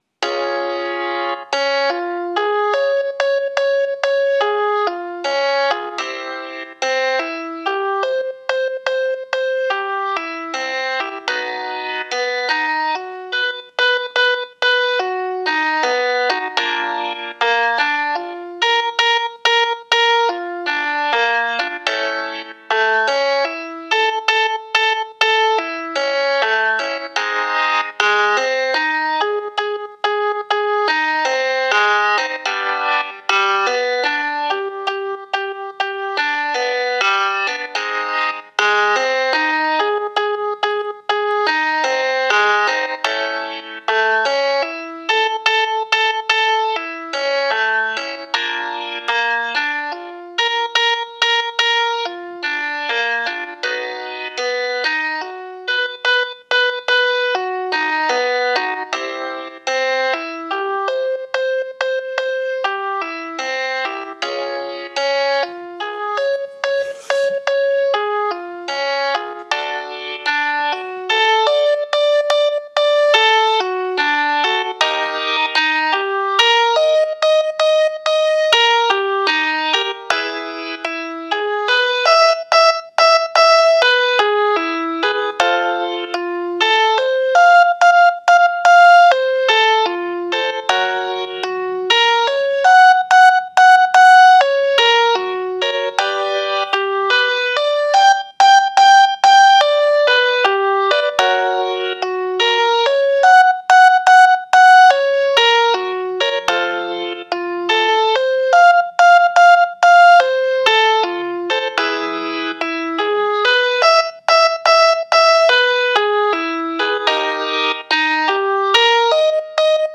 FREE VOCAL EXERCISE 2
ESSENTIAL VOCAL WARM UPS